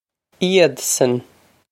Iadsan ee-od-son
Pronunciation for how to say
ee-od-son
This is an approximate phonetic pronunciation of the phrase.